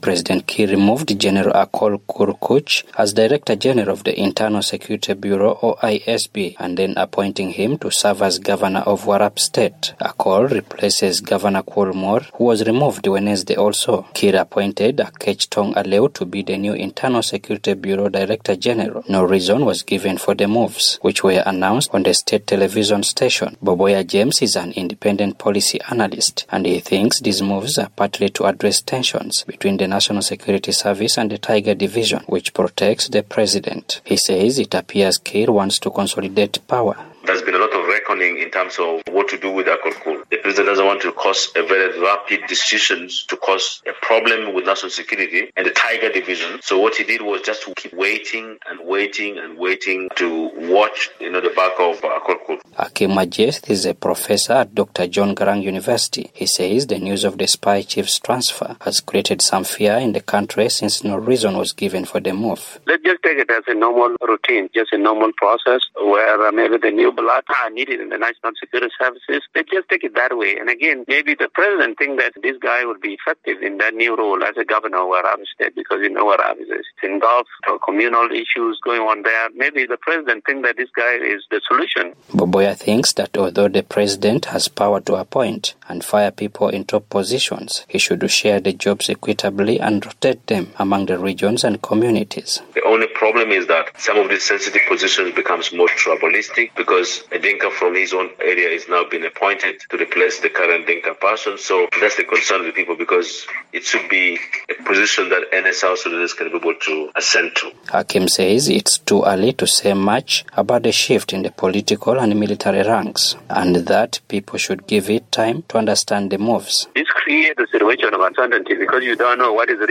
reports from Juba